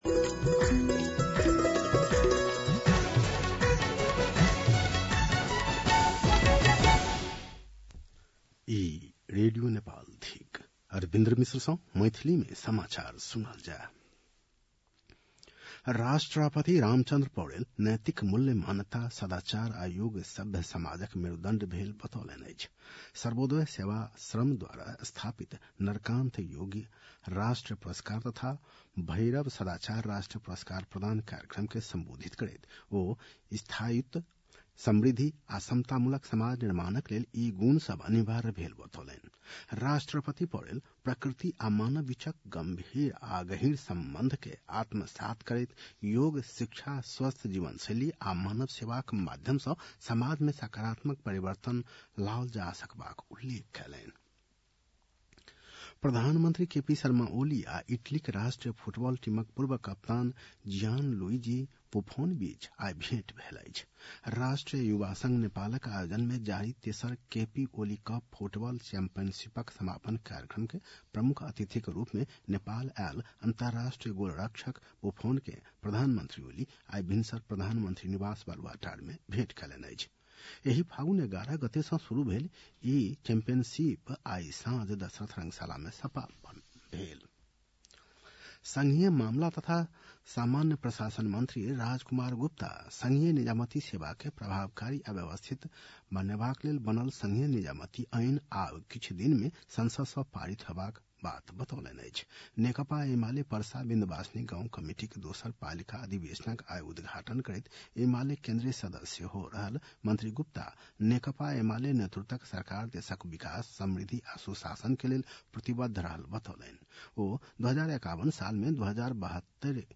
मैथिली भाषामा समाचार : २० फागुन , २०८१